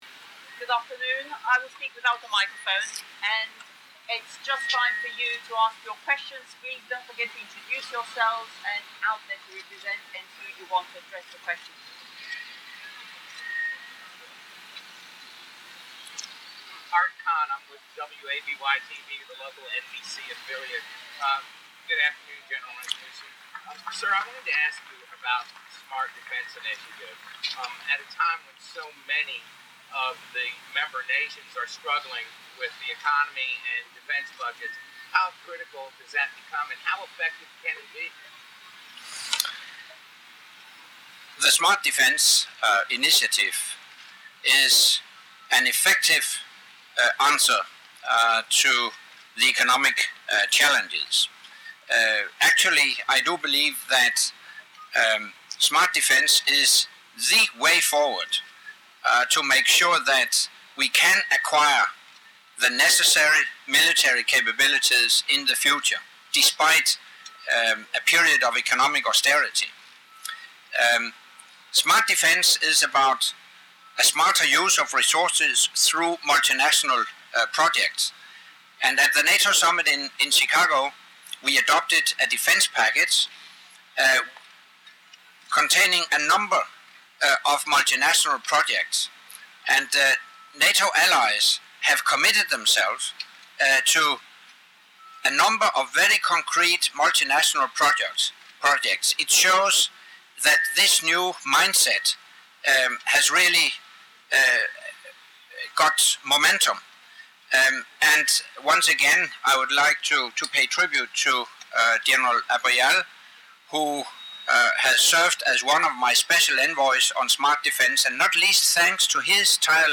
Le secrétaire général de l'OTAN assiste à une cérémonie de passation de commandement au Commandement allié Transformation
Le général Jean-Paul Paloméros, de l'armée de l’air française, a officiellement pris ses fonctions à la tête du commandement suprême allié Transformation le vendredi 28 septembre, lors d'une cérémonie de passation de pouvoir qui s'est tenue à Norfolk (Virginie), en présence du secrétaire général de l'OTAN, Anders Fogh Rasmussen, du secrétaire général délégué, l'Ambassadeur Alexander Vershbow, du vice-ministre français de la Défense, Kader Arif, des représentants permanents des pays de l'Alliance, du président du Comité militaire, le général Knud Bartels, et du président délégué de l'instance collégiale des chefs d'état-major, l'amiral américain James A. Winnefeld.